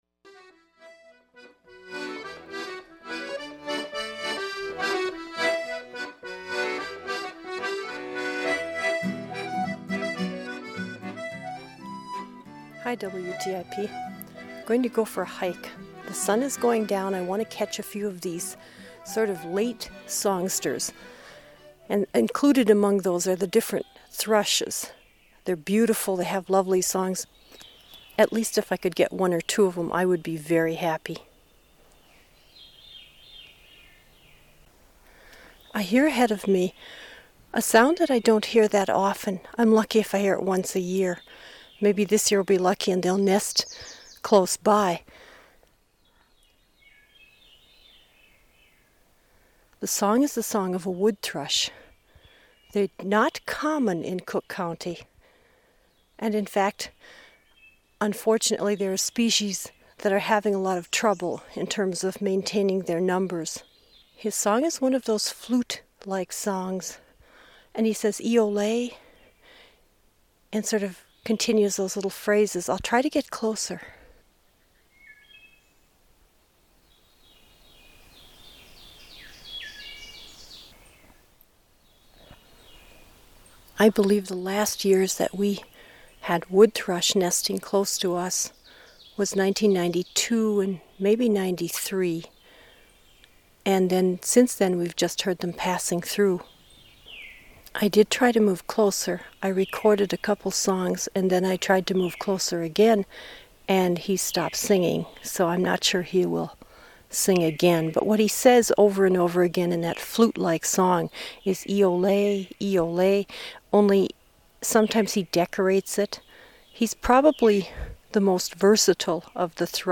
Recorded in the fields and woodlands of Northeastern Minnesota